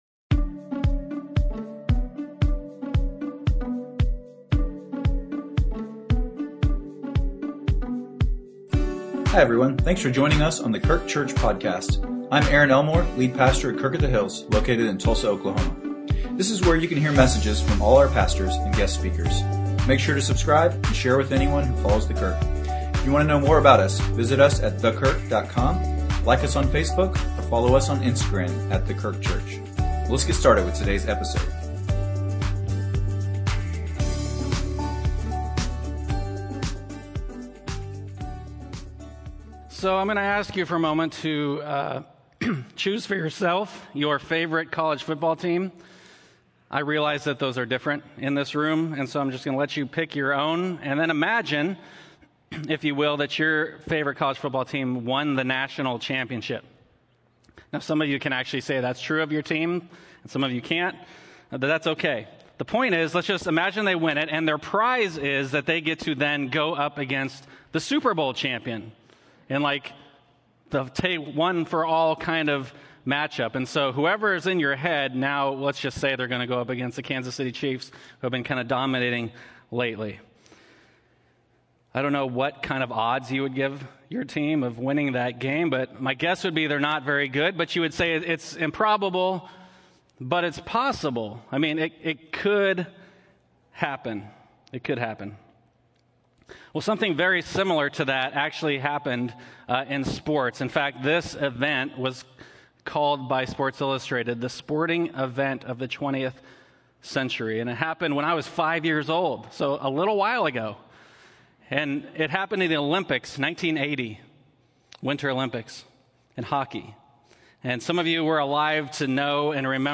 Sermons | The Kirk - Tulsa, OK